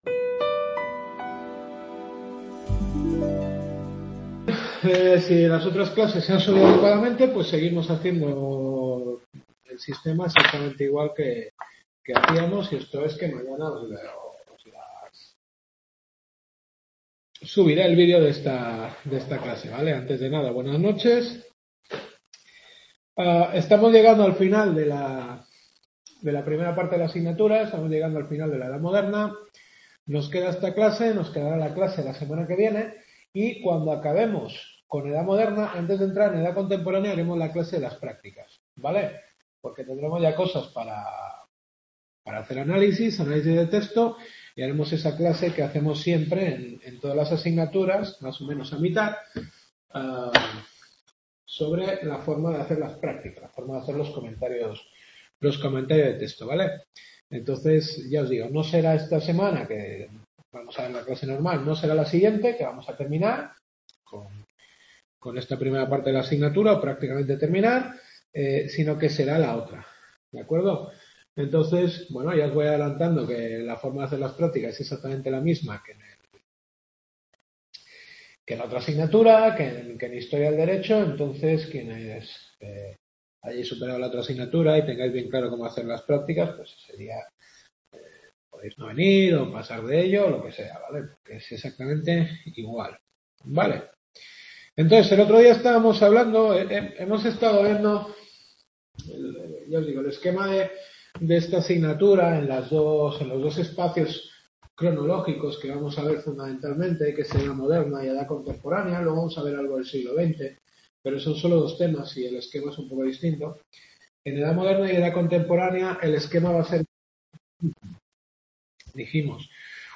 Quinta clase.